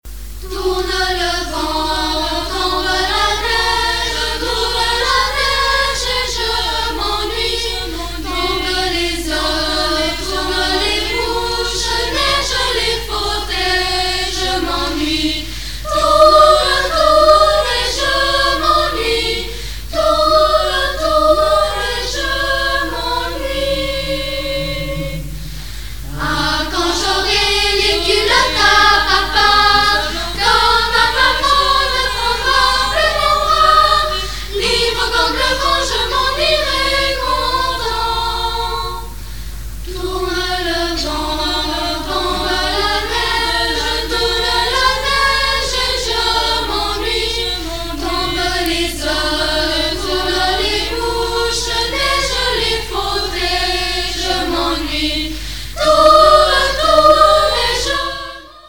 1986 - 1987 - Choeur d'enfants La Voix du Gibloux